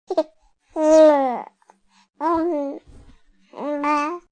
babycoo.ogg